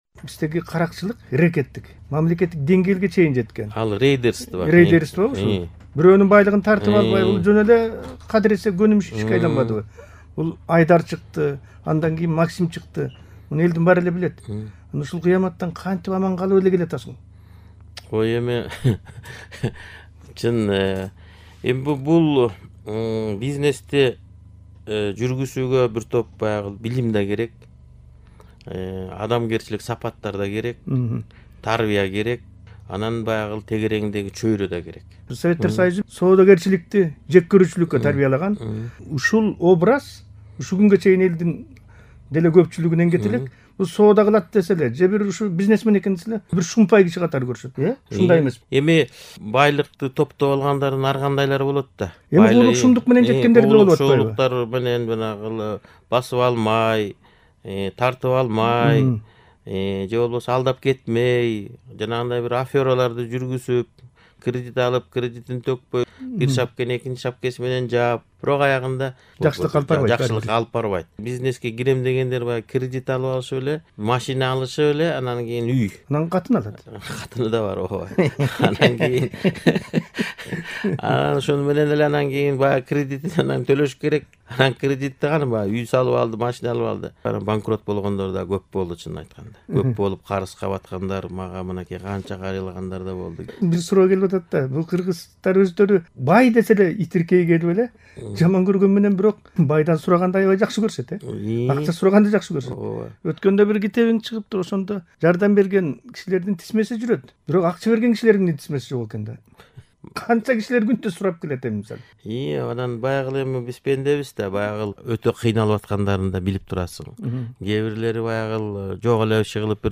Аскар Салымбеков менен маек